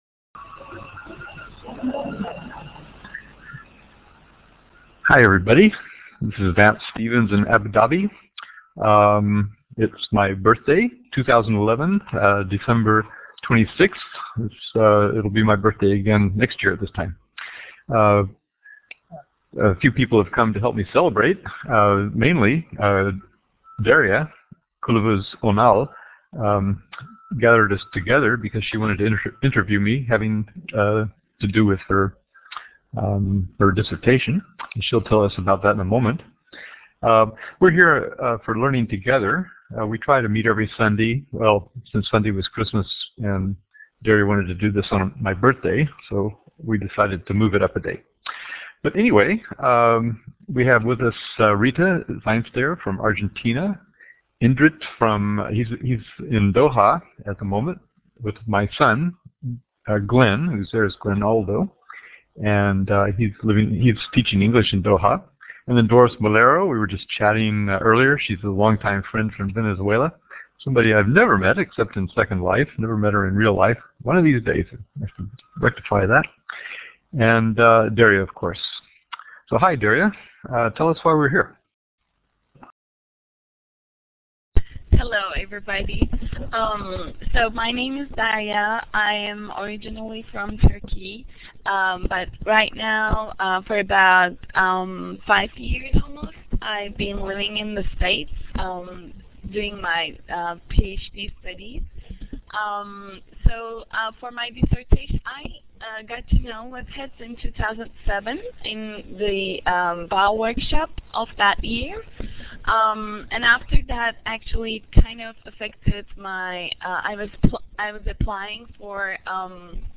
Where? Webheads Virtual Room in Bb Collaborate / Elluminate